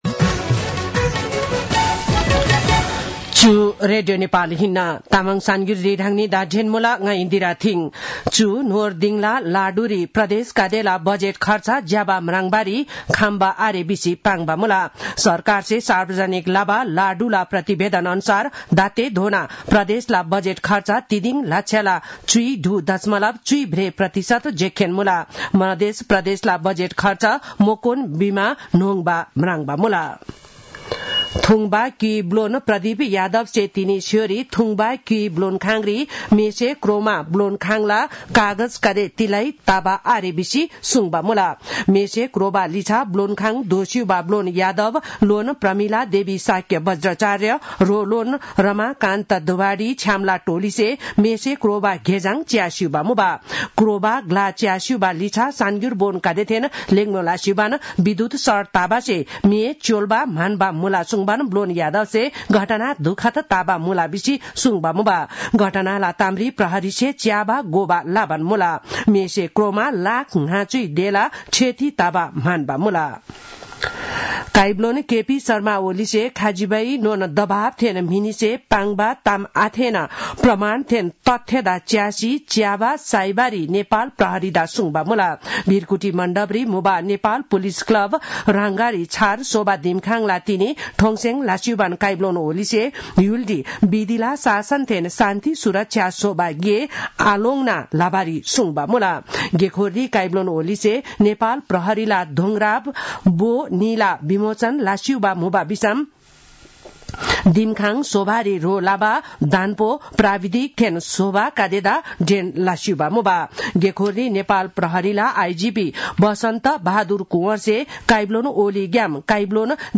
तामाङ भाषाको समाचार : २७ माघ , २०८१